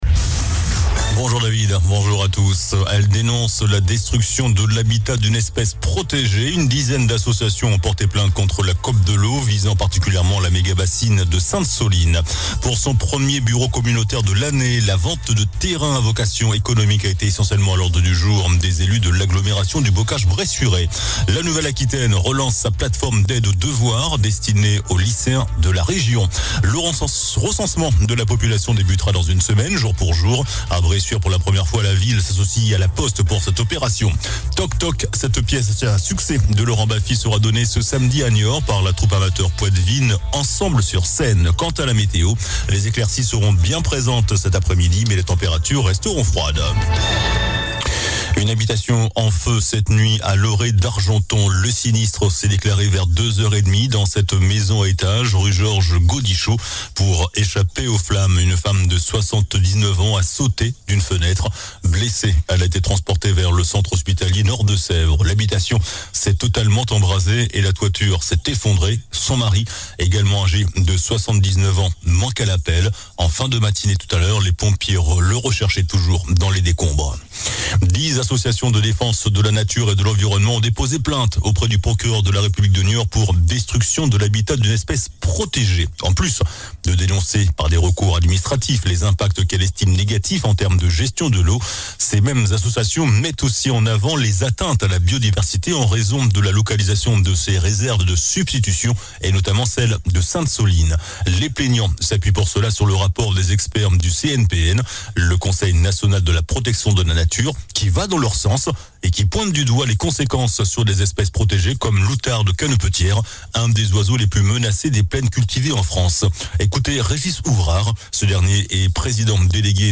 JOURNAL DU JEUDI 11 JANVIER ( MIDI )